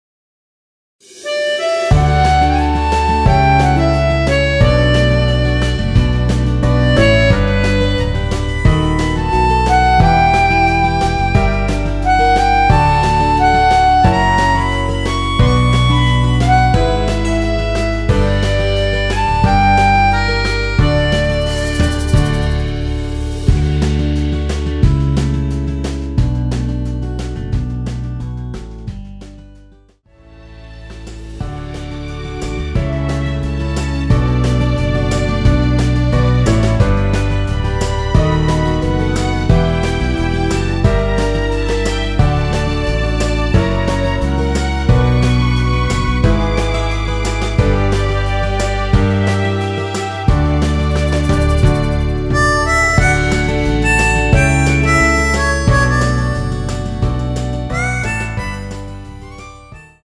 원키에서(+4)올린 MR입니다.
앞부분30초, 뒷부분30초씩 편집해서 올려 드리고 있습니다.